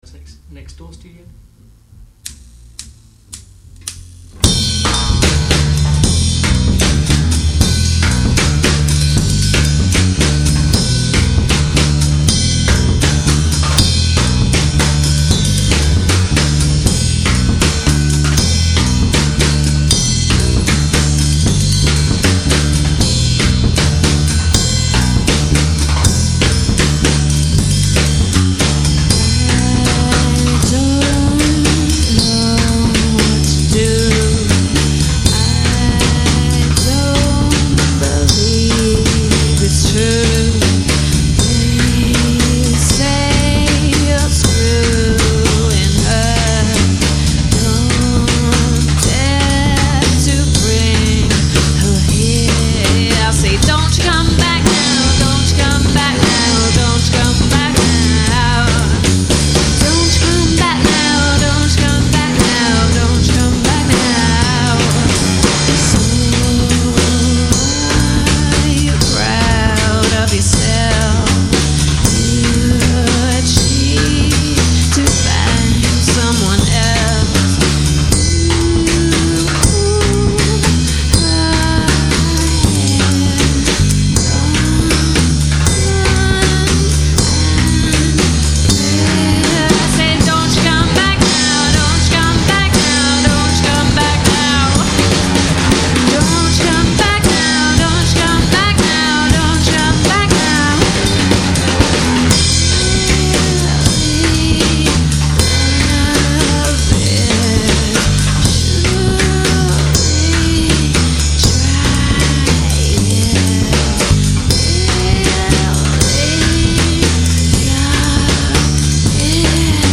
Two mikes, one take, no problem!